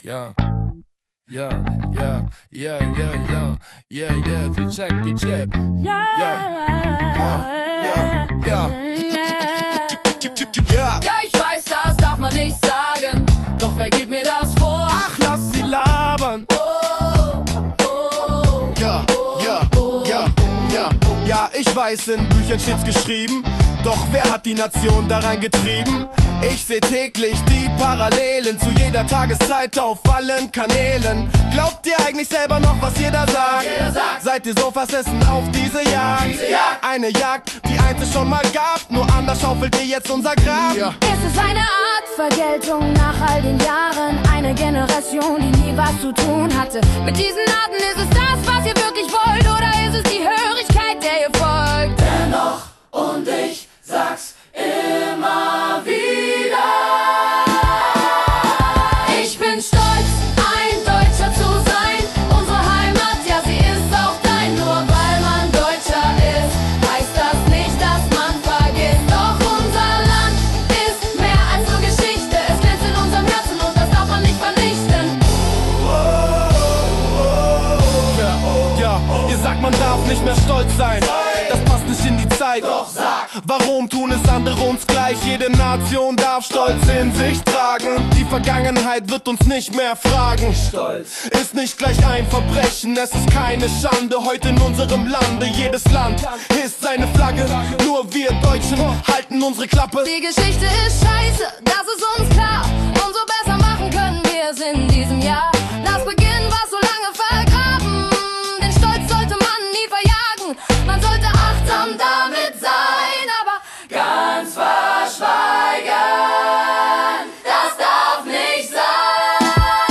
Hier eine Auswahl an Musik, die durch KI erzeugt wurde.